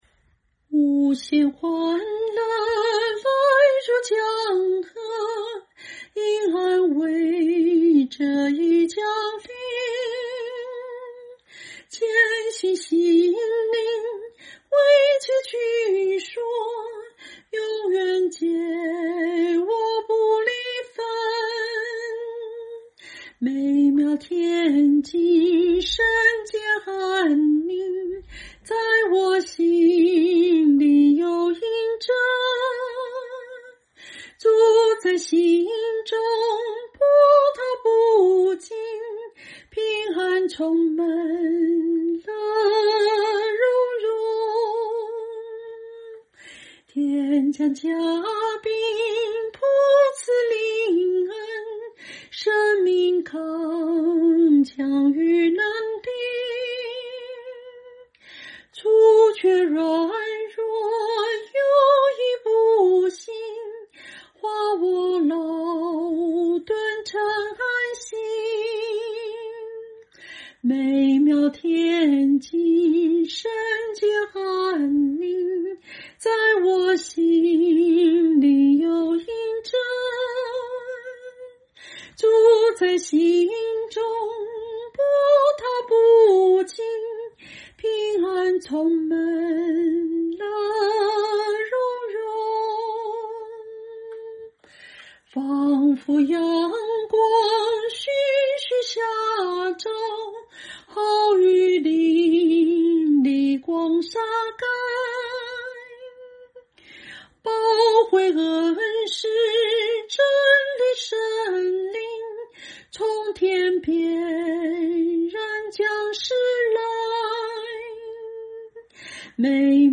清唱    伴奏